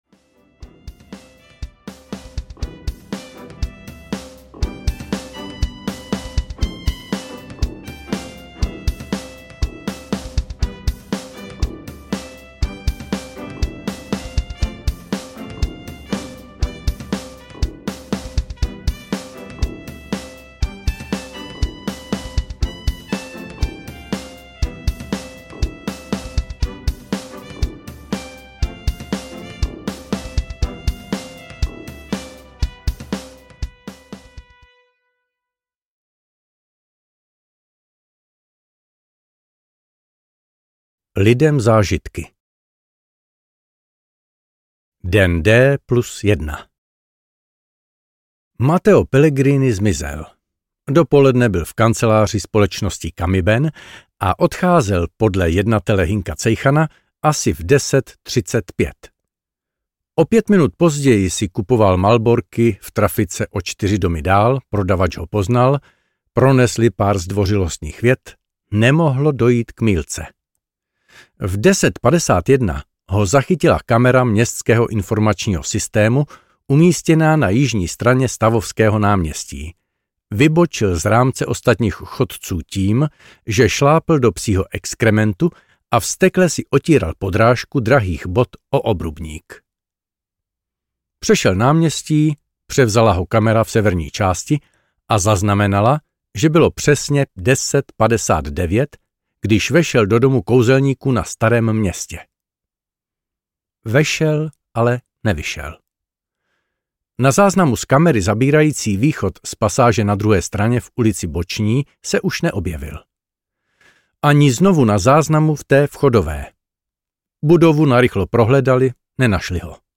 Vymazlené vraždy 3 audiokniha
Ukázka z knihy